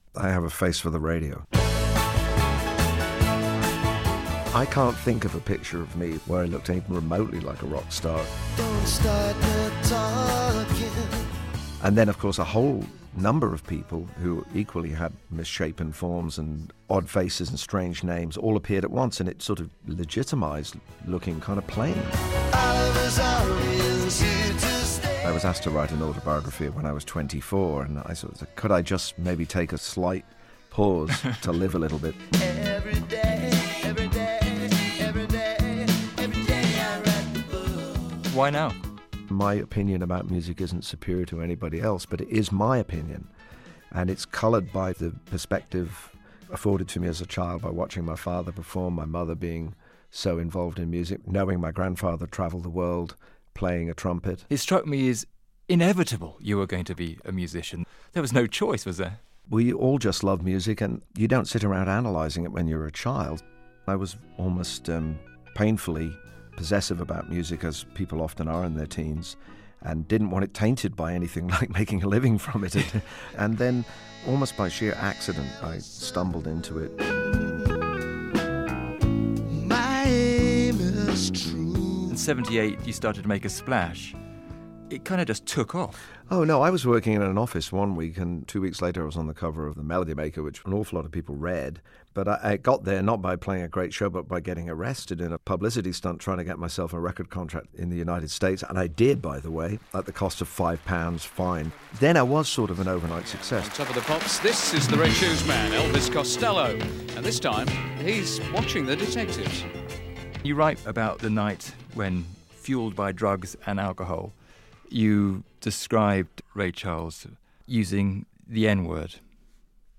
Elvis Costello talks about his new autobiography to Will Gompertz on BBC Radio 4's Today programme